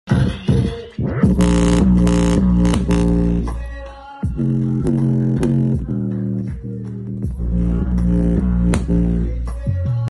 Jbl Charge 3 Bass Test Sound Effects Free Download